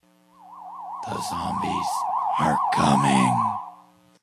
zombieComing.ogg